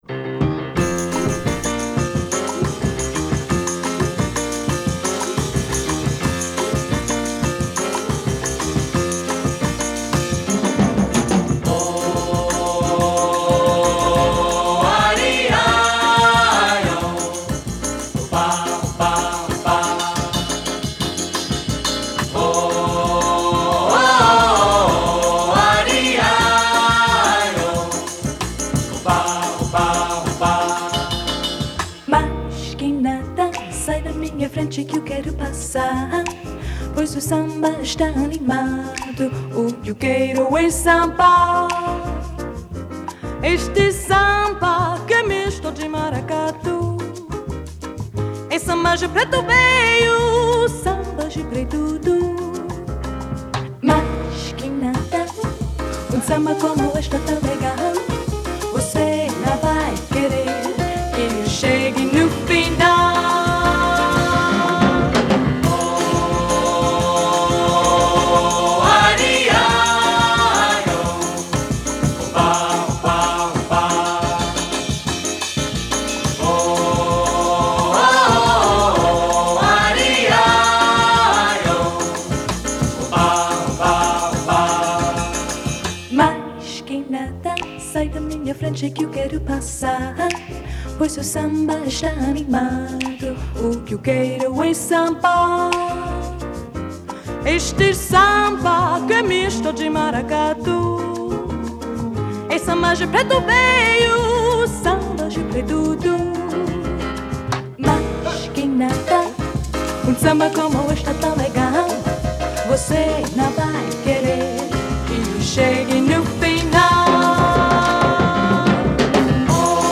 1963   Genre: Samba   Artist